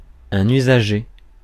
Ääntäminen
US : IPA : [ˈju.zɚ]